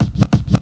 Scratch toomp.wav